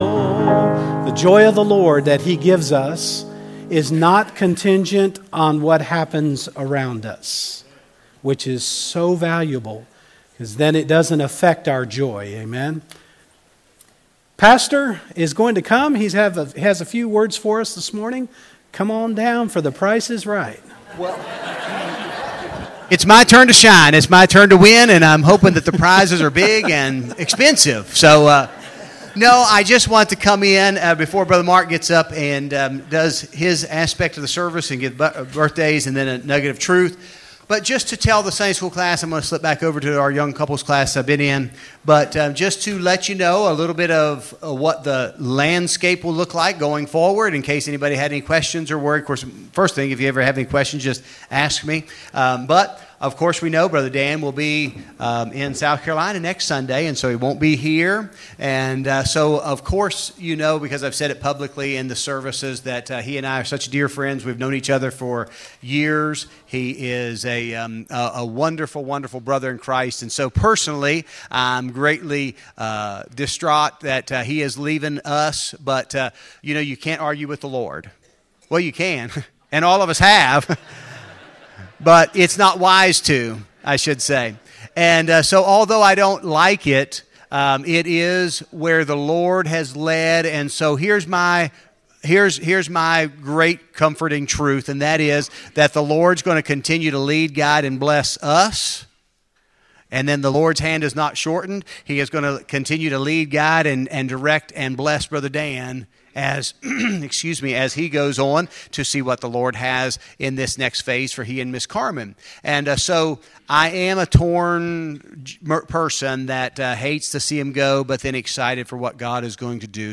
01-28-24 Sunday School Lesson | Buffalo Ridge Baptist Church